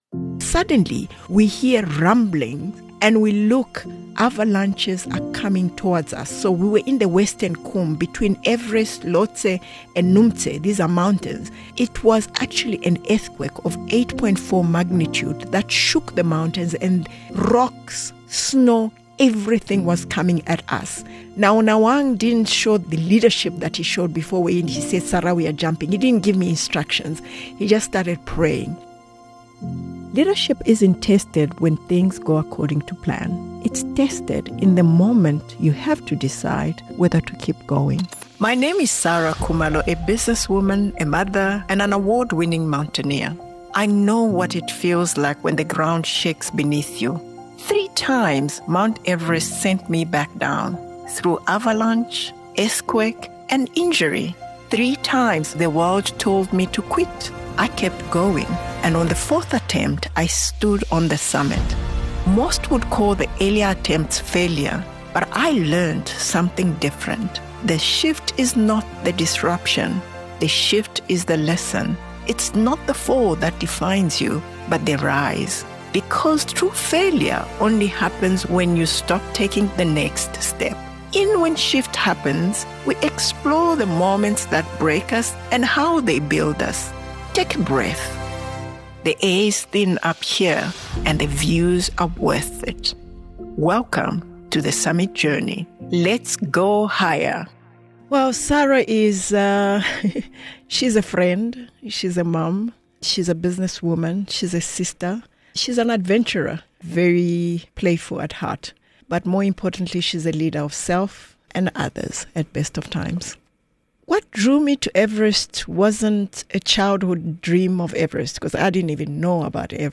Before she could ask others how they navigated change, Saray Khumalo had to live it herself. In this opening episode of When Shift Happens, Saray shares her personal story in her own words and reflects on the moments that shaped her leadership, her resilience and her purpose.